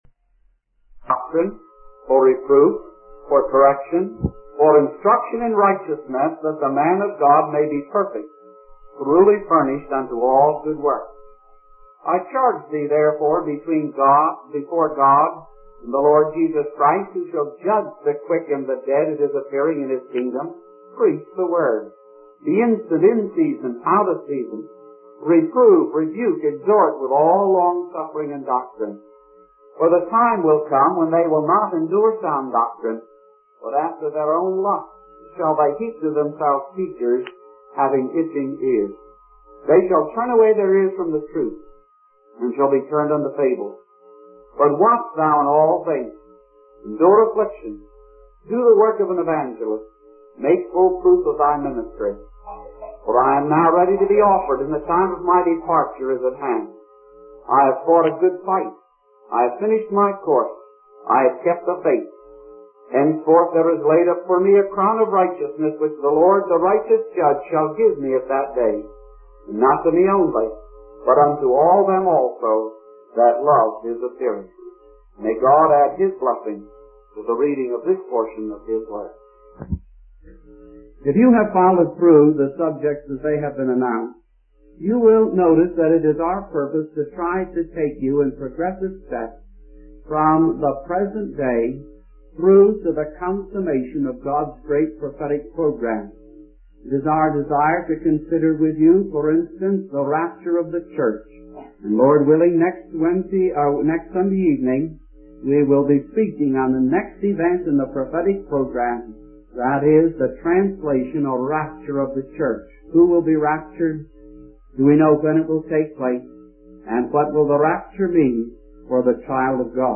In this sermon, the preacher discusses the concept of being a citizen of heaven while living on earth. He emphasizes that believers should eagerly anticipate the return of Jesus Christ, who will transform their mortal bodies into glorious ones.